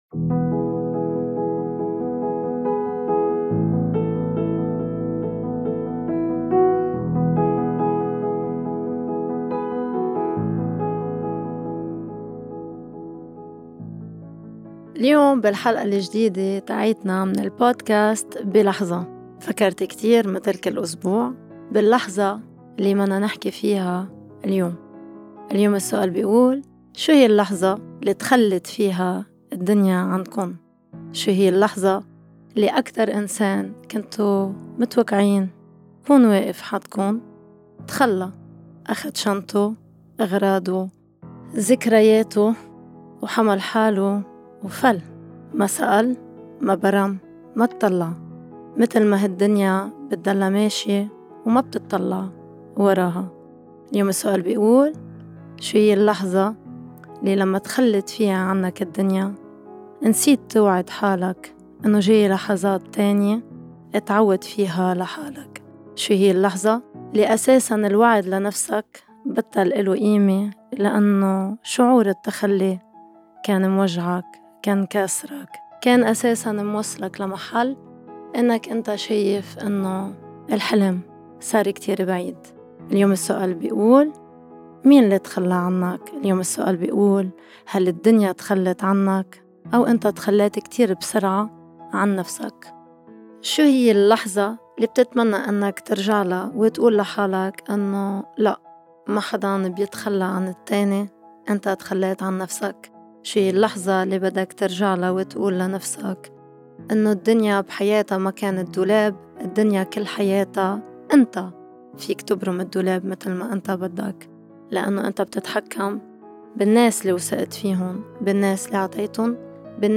الجزء الثاني: مناقشة مع الضيف حول (هل ممكن نآمن بالحب بالرغم من…؟)